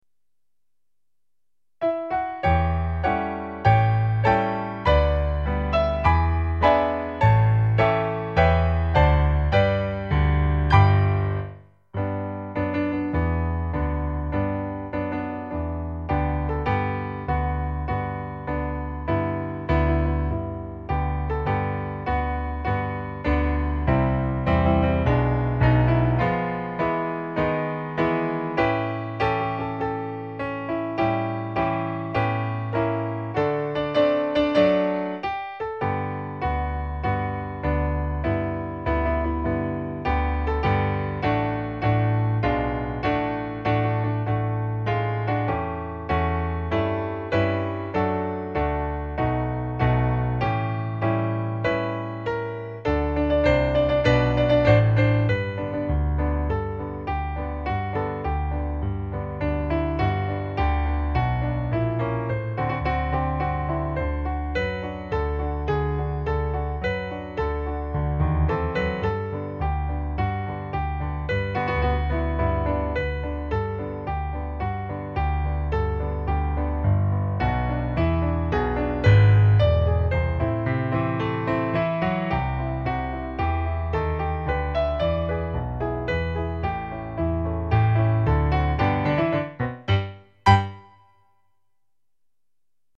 Time Signature: C
Key: G
Notations: Tempo di marcia